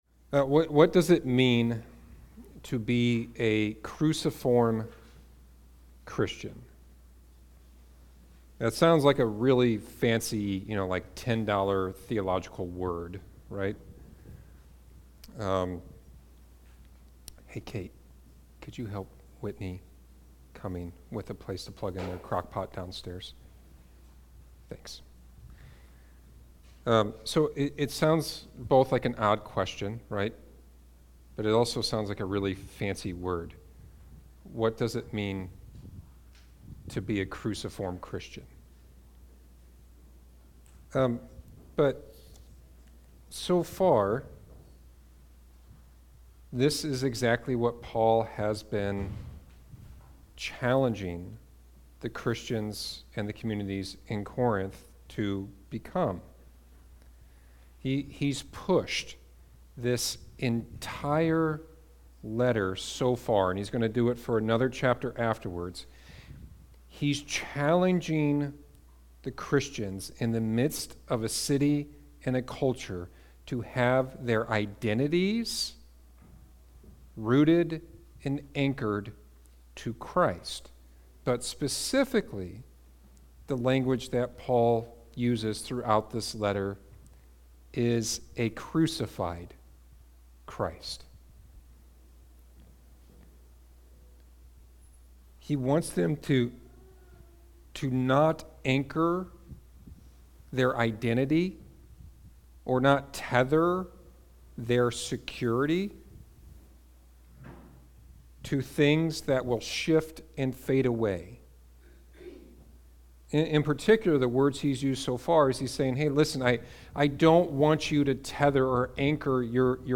Sermons | Beacon Church